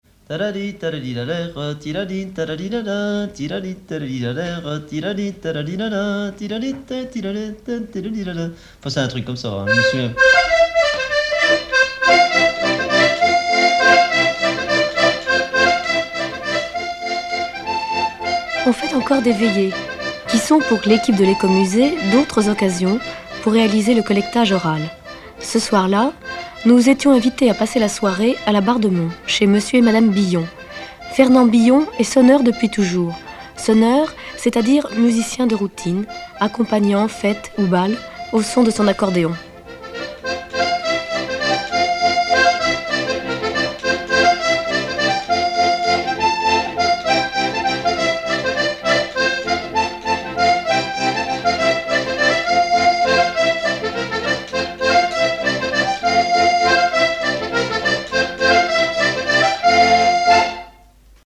Interview
Emisson Départementale, sur Radio France Culture
Catégorie Témoignage